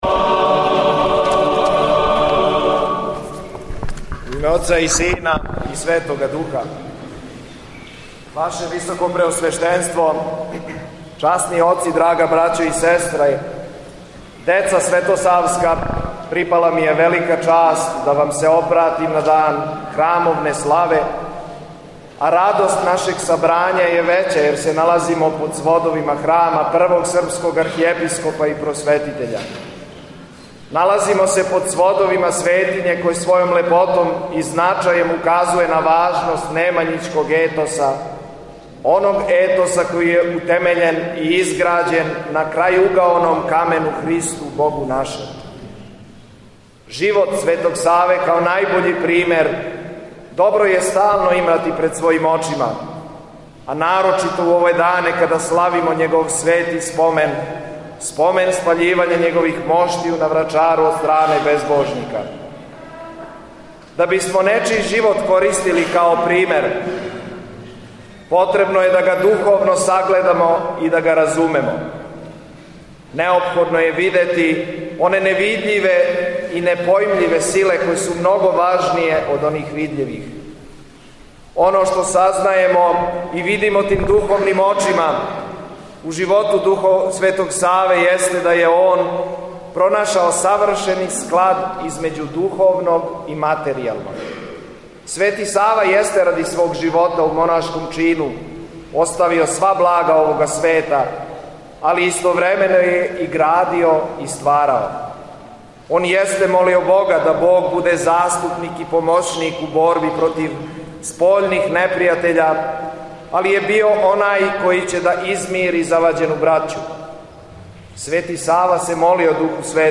На крају свечаног бденија, верницима се обратио Митрополит Јован, честитавши им храмовну славу:
Беседа Његовог Високопреосвештенства Митрополита шумадијског г. Јована